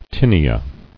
[tin·e·a]